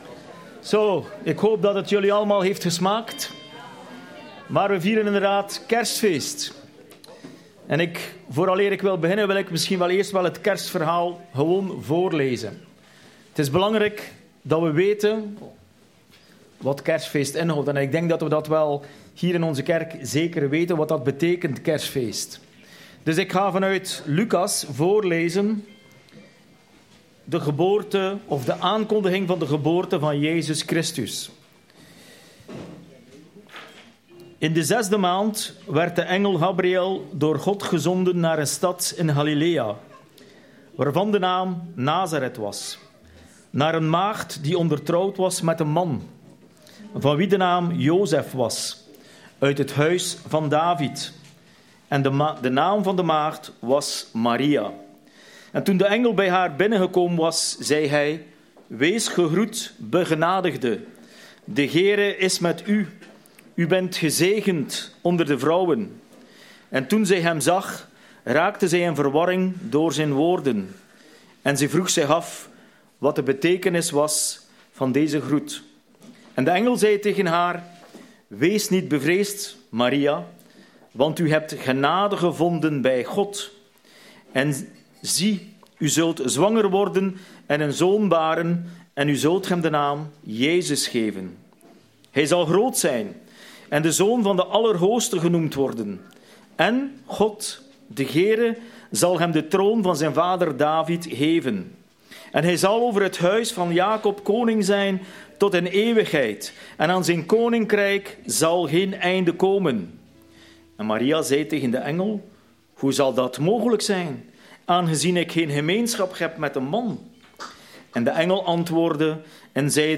Lucas 2:32 Dienstsoort: Familiedienst KERSTDIENST ZALIG EN GEZEGEND KERSTFEEST IEDEREEN !